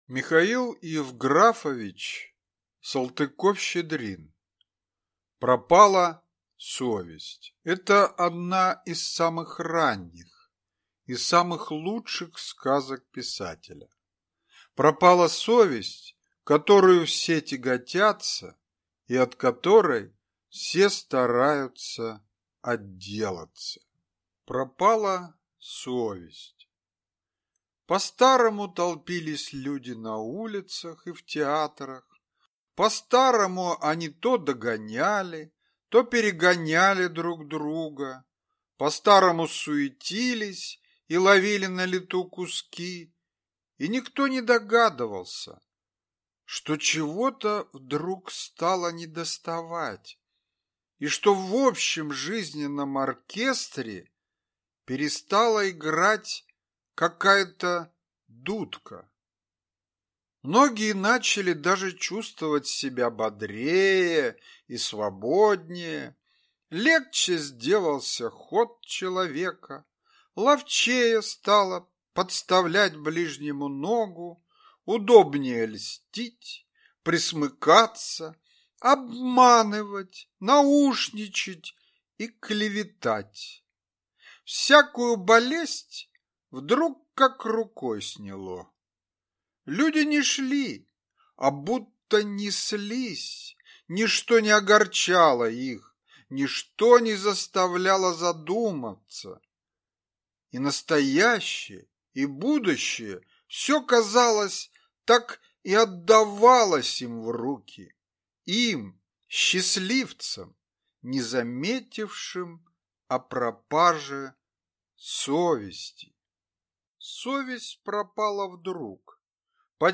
Аудиокнига Пропала совесть | Библиотека аудиокниг